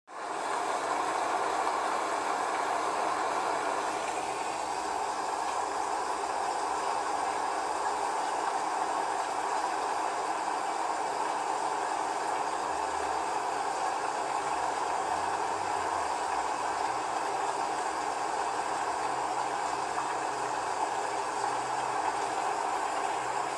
Lluvia en Cartago CARTAGO